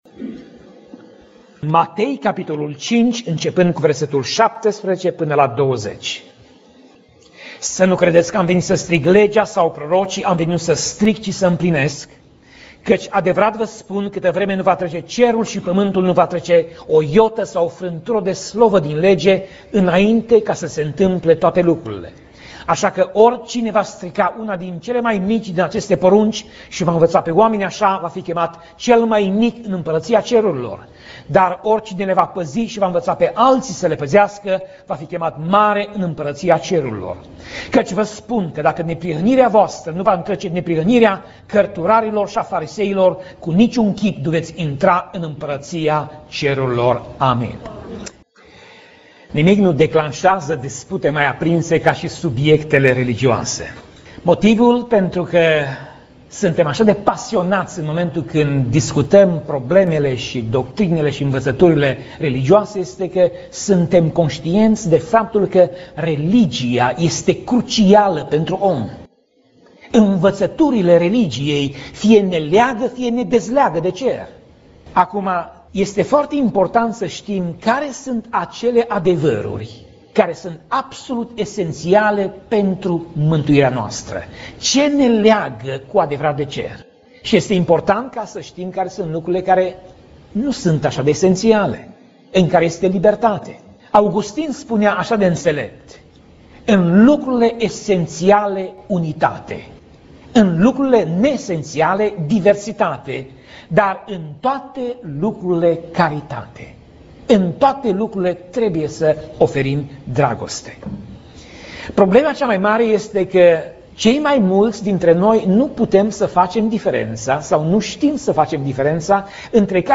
Pasaj Biblie: Matei 5:17 - Matei 5:19 Tip Mesaj: Predica